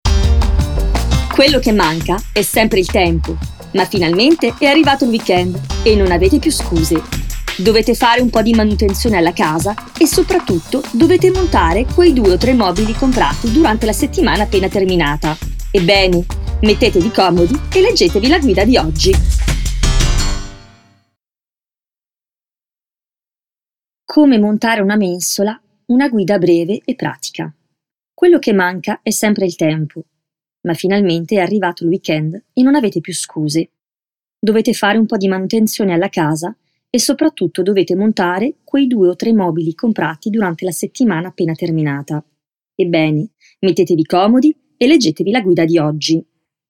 Bande Démo
Demo voix off italien